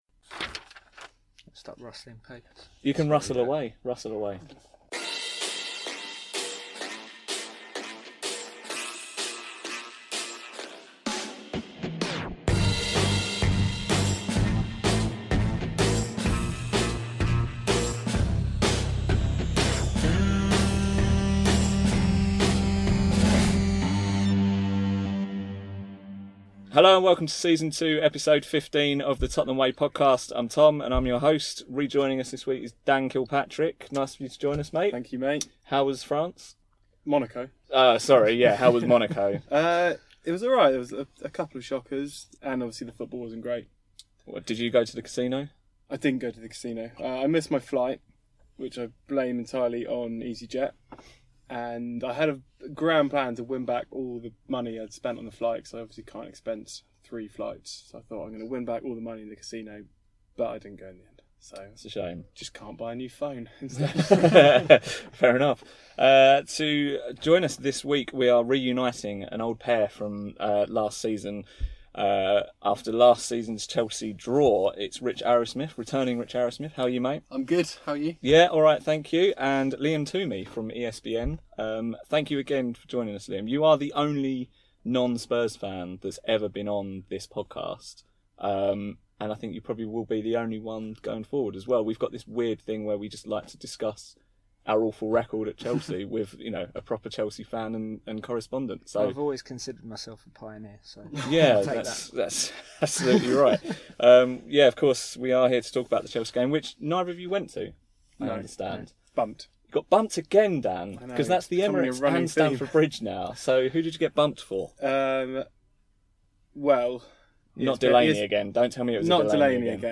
Nor is the ending, which was chopped off for some reason.